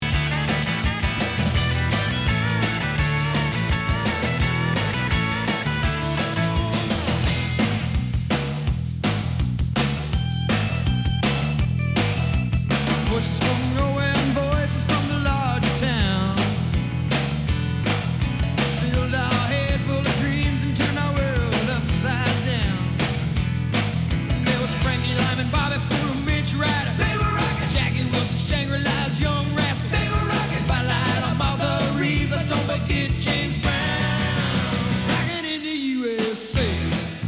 Пример записи радио #1